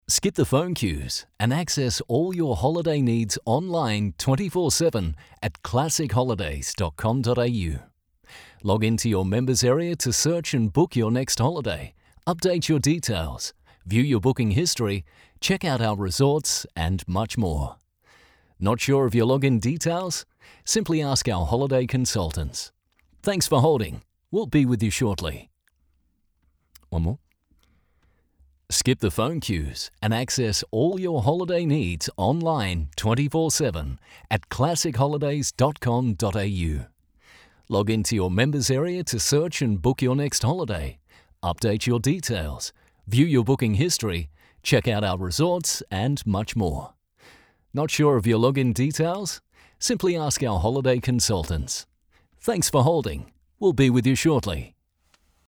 Award-winning Australian Voice. versatile, clear, and seriously experienced.
• On Hold
• Professional Voice booth – acoustically treated.